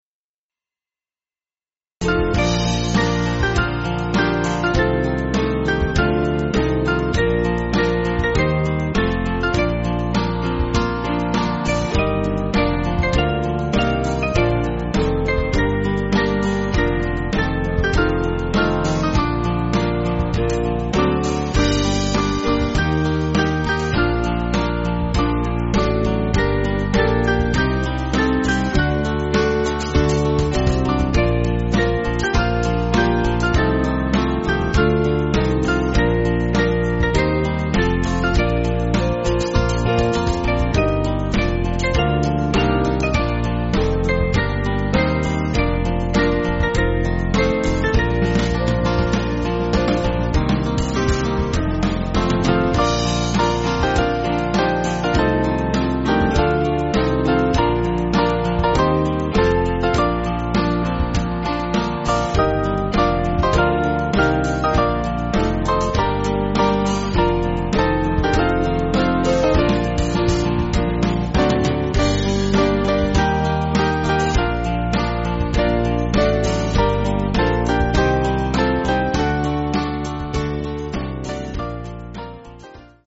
Small Band
(CM)   3/Bb